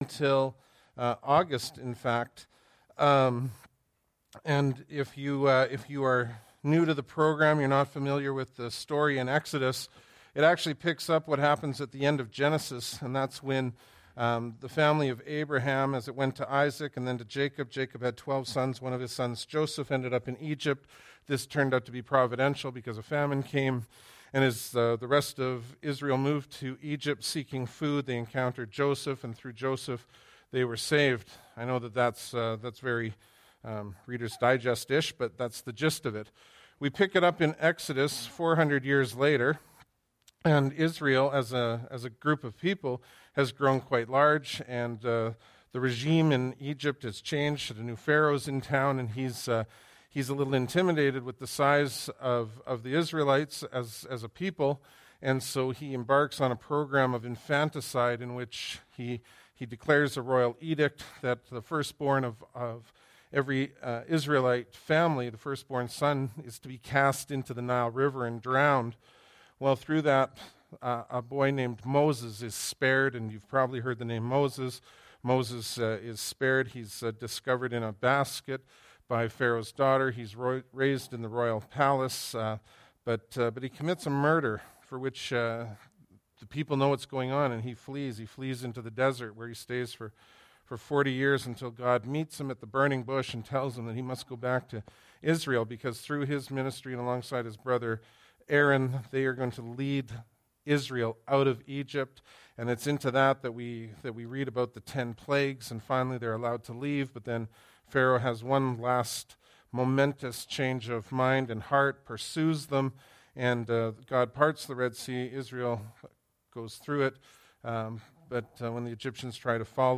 Bible Text: Exodus 20:22-26 | Preacher